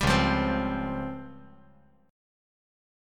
C#M#11 Chord
Listen to C#M#11 strummed